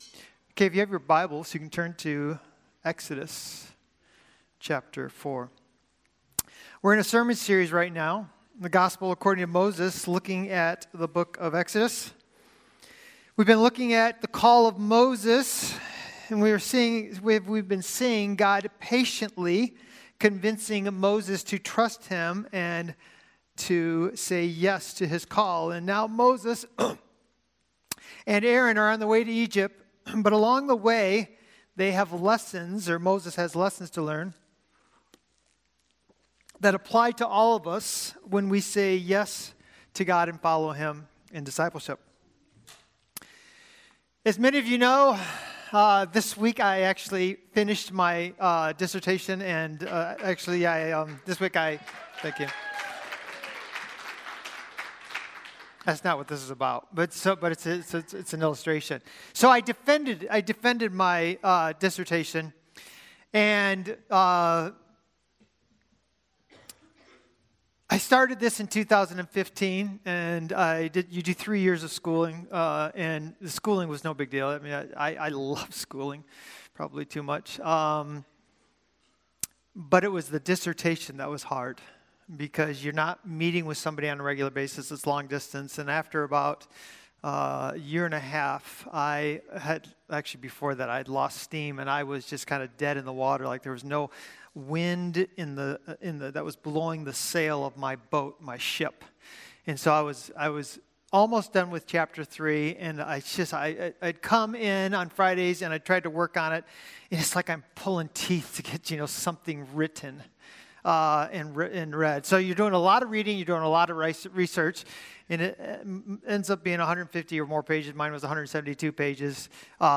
Sermons | Christian Community Church